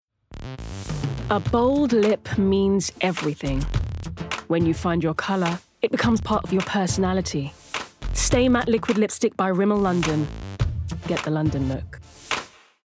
20/30s Neutral/London,
Confident/Engaging/Soothing